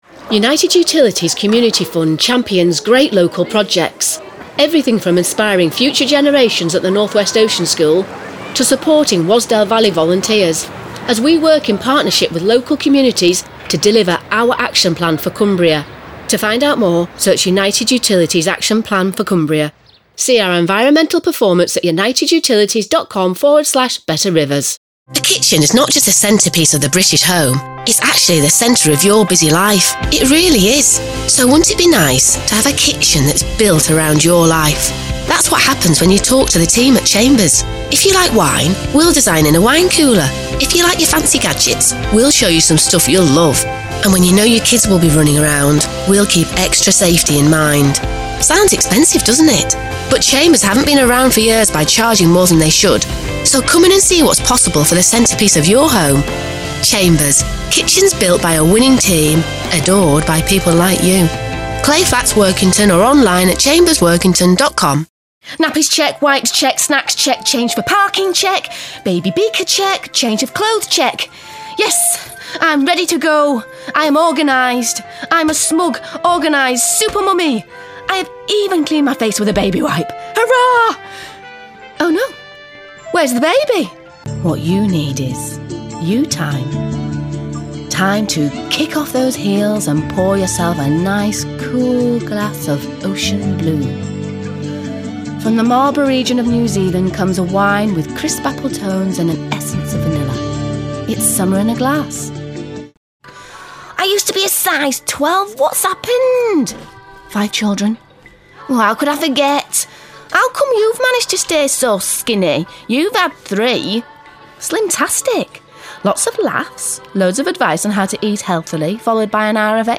Commercial
Documentary
Northern (English)
Thirties, Forties
Actors/Actresses, Attitude, Character/Animation, Modern/Youthful/Contemporary, Natural/Fresh, Quirky/Interesting/Unique, Upbeat/Energy